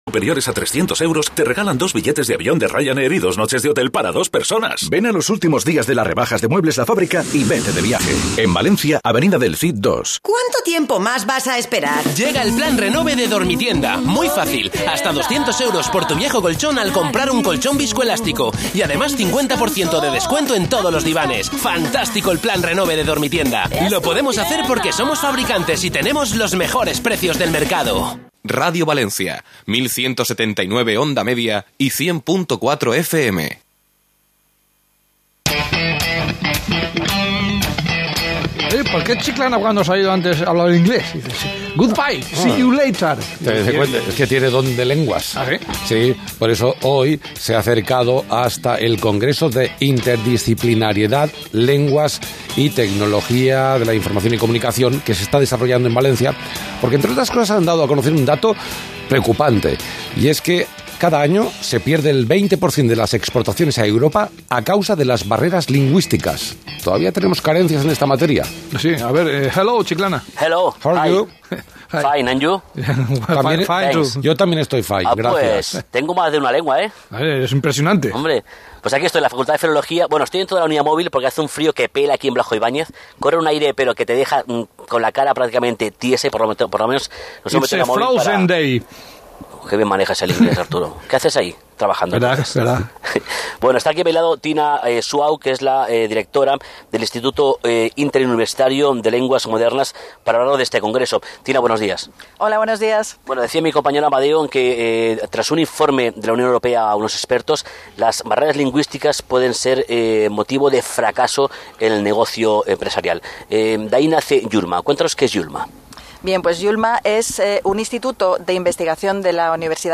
Entrevista radiofónica